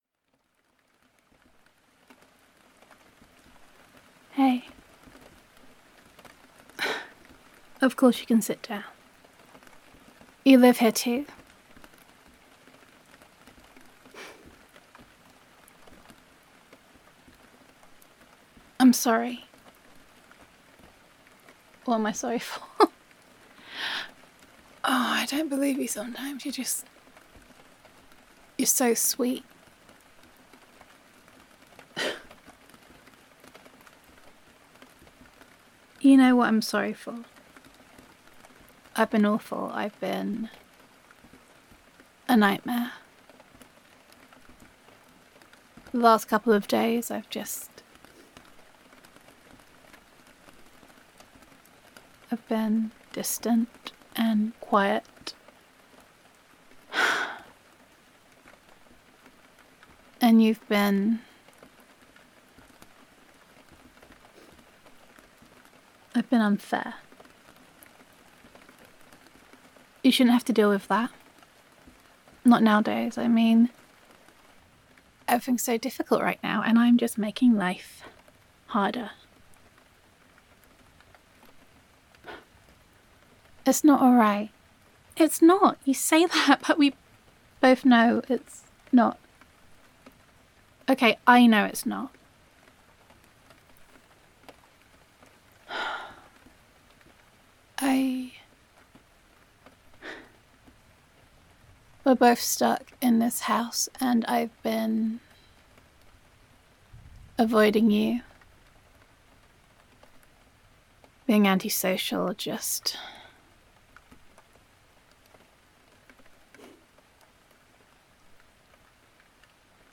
[F4A] Sweet Succour [Girlfriend Roleplay][Lock Down][Reverse Comfort][I’m Adorkable and I Know It][Playful][Domestic Bliss][Comfort in These Tough Times][Gender Neutral][Comforting Your Overwhelmed Girlfriend]